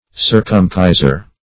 \Cir"cum*ci`ser\